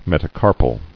[met·a·car·pal]